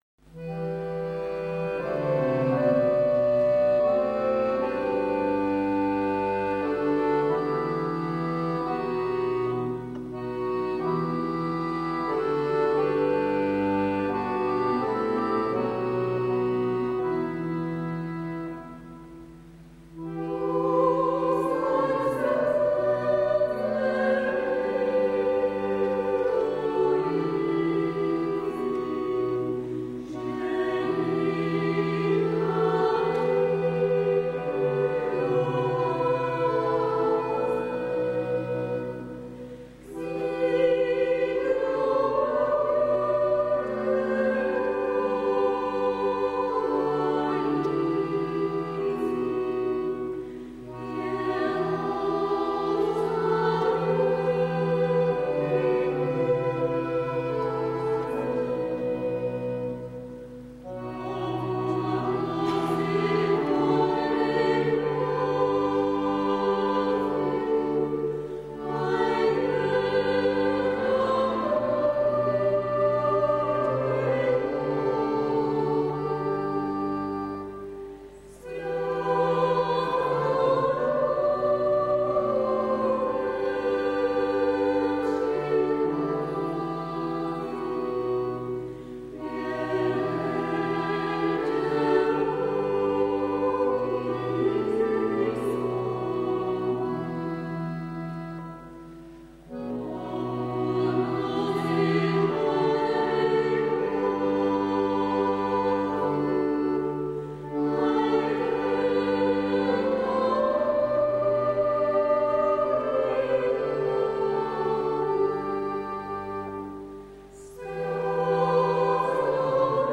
Liberec - Ruprechtice 14.9.2003
Adam Michna z Otradovic: Loutna česká (s LAURUS)